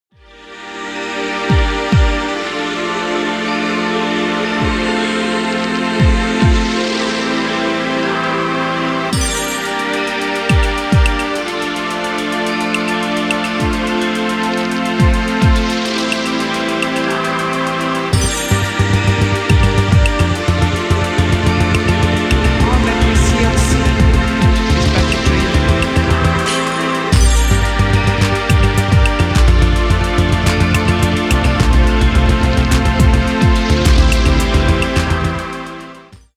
120BPM以下のリラックスしたテンポで刻まれるブレイクビーツ